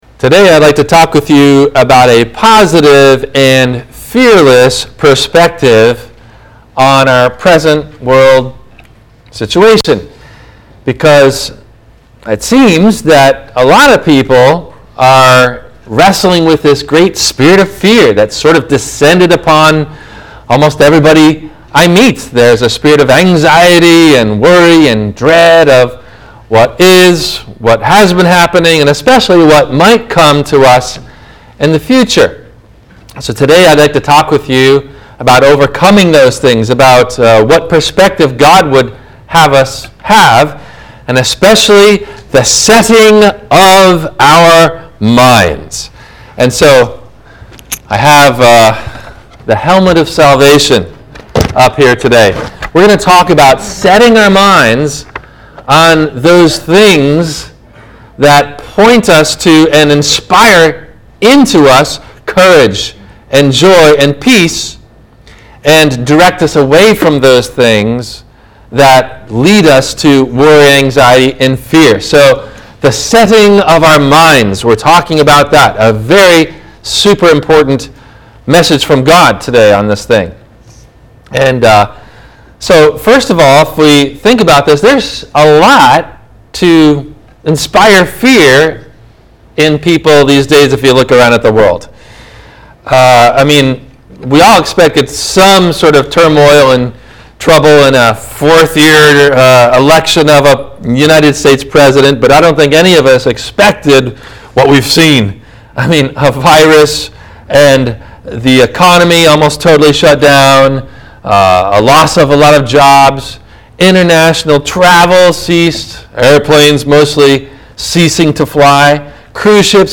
A Positive View of The Present Crisis – WMIE Radio Sermon – September 07 2020
No Questions asked before the Radio Message.